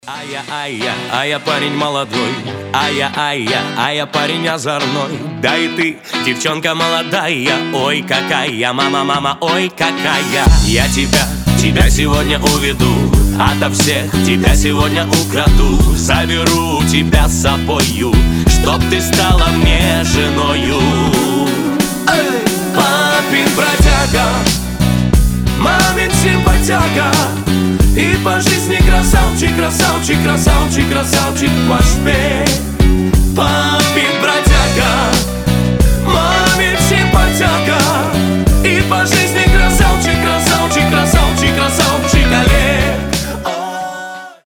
поп
позитивные
веселые